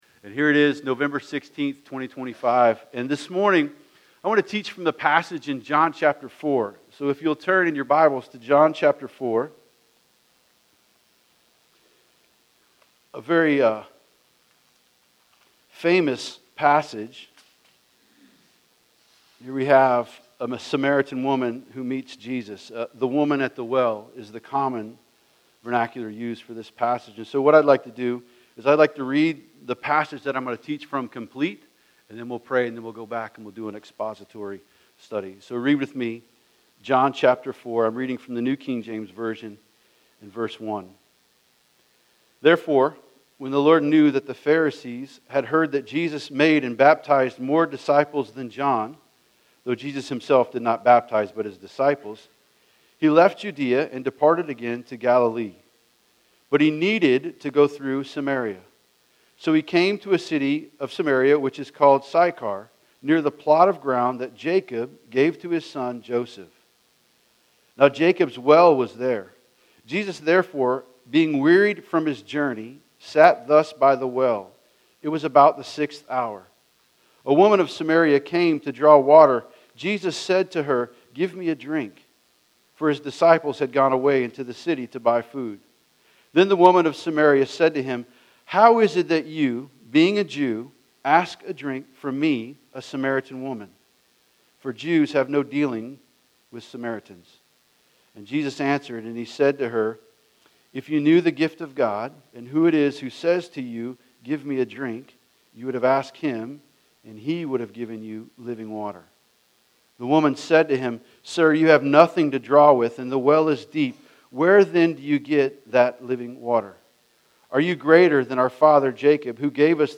by Calvary Chapel Leesburg | Nov 16, 2025 | Sermons